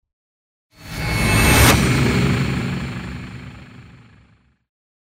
На этой странице собраны звуки внезапного появления: резкие переходы, неожиданные всплески, тревожные сигналы.
Звук появления для монтажа